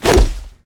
grenade throw.ogg